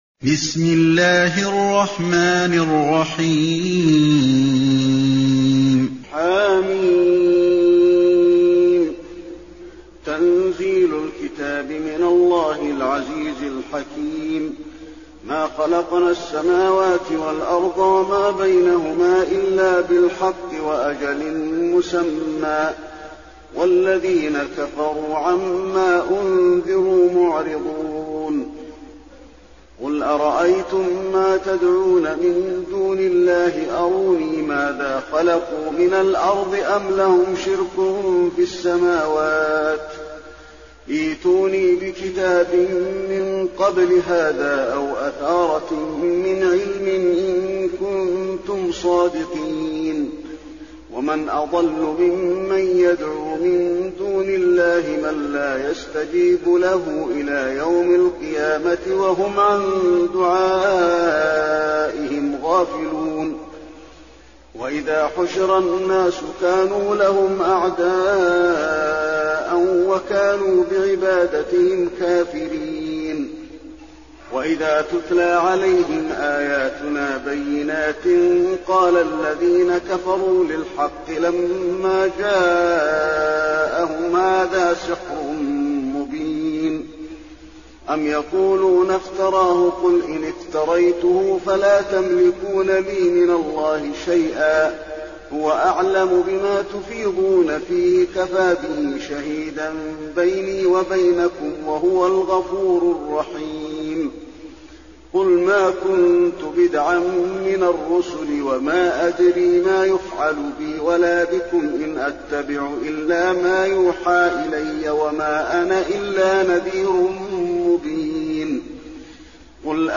المكان: المسجد النبوي الأحقاف